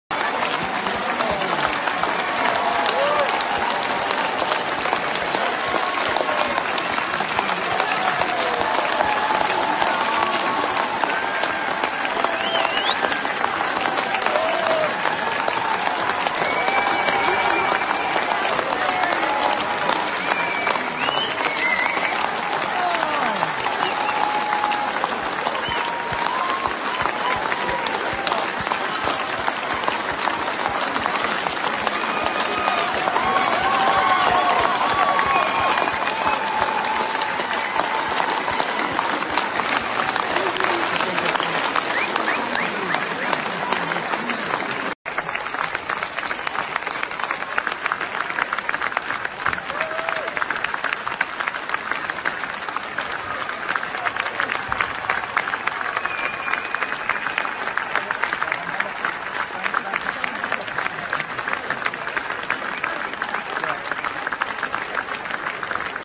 Am 8. März 2023 trat Daniele Ganser in Kiel in einer ausverkauften Halle auf. Als Professor Sucharit Bhakdi auftauchte (der Mann der unzähligen Millionen Menschen durch seine Aufklärung das Leben gerettet hat) erhob sich die Menge und es gab stürmischen Applaus.
Er kommt einfach rein, sagte nichts und tausende Menschen applaudieren ununterbrochen.